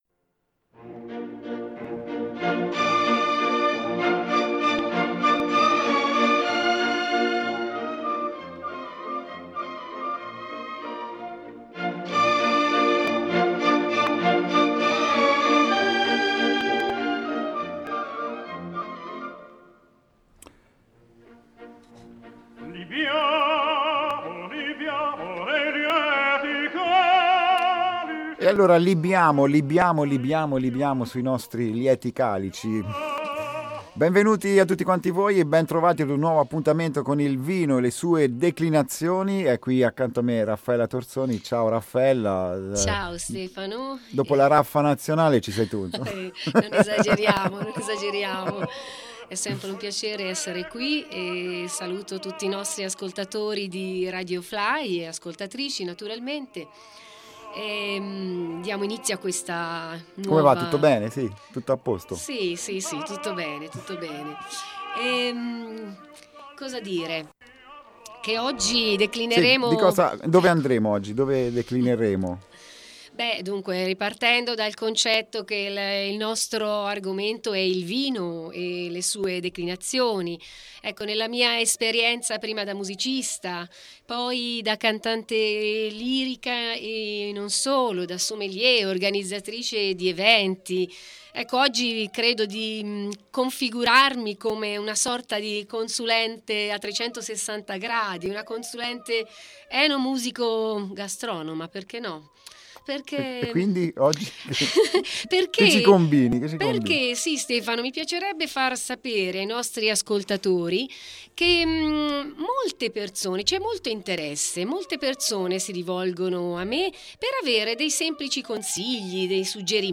con apertura a sabrage e brindisi finale per RadioFly